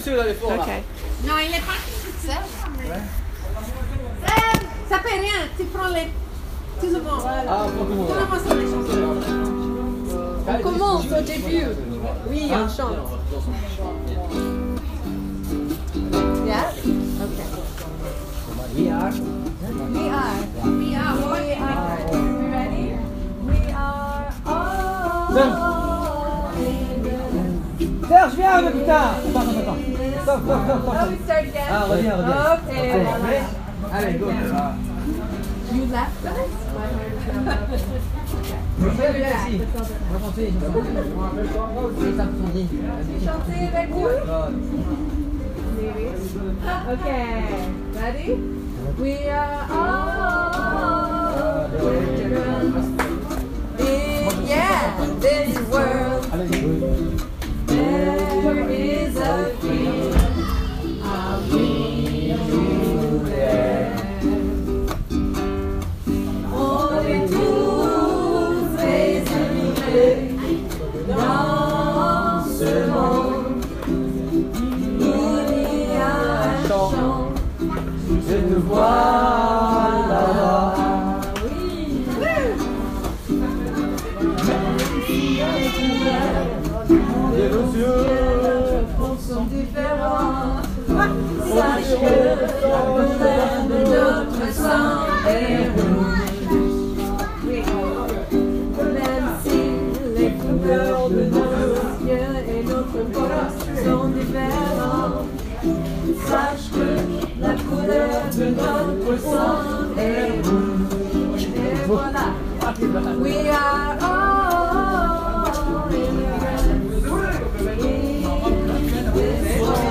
The following is a clip from a runthrough of the song on the afternoon we wrote it at the center: